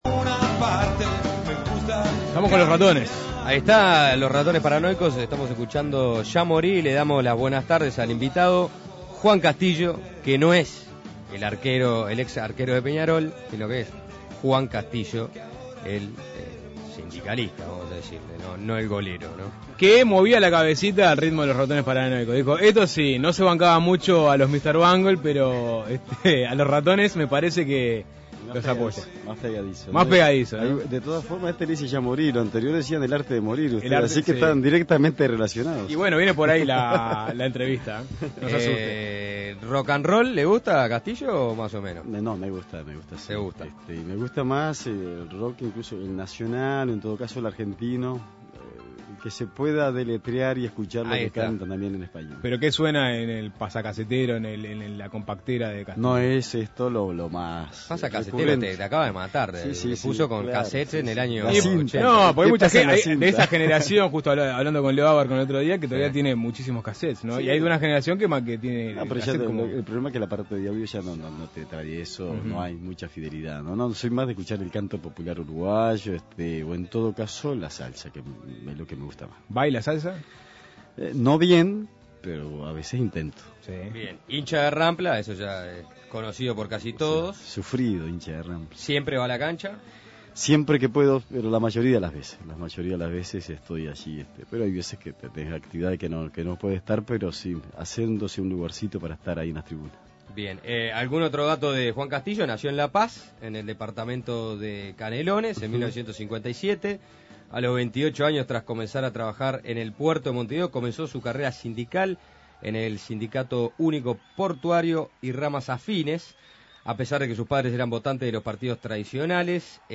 Desde su rol de coordinador gral. del PIT-CNT se ha consolidado como uno de los dirigentes sindicales más importantes. En diálogo con Suena Tremendo Juan Castillo se refirió al posible paro gral, las elecciones del PIT-CNT y a la interna del Partido Comunista